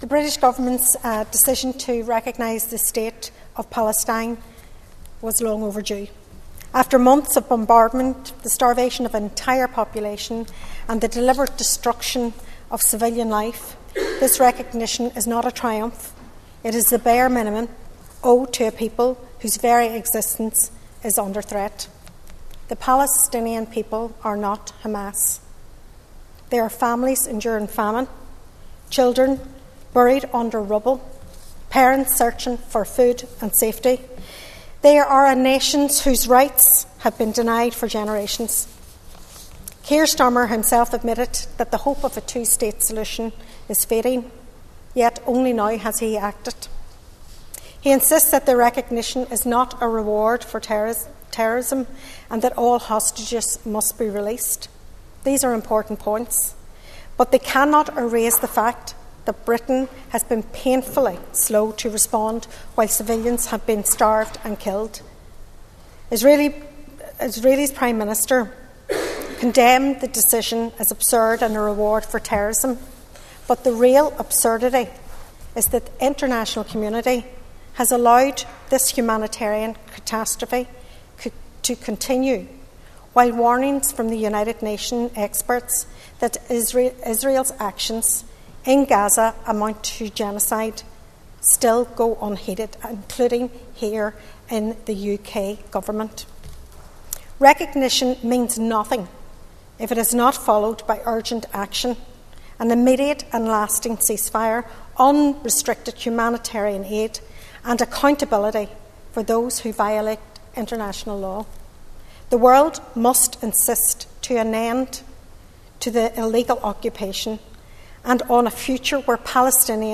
Foyle MLA Sinead McLaughlin has told the Assembly today that the recognition of Palestine is long overdue.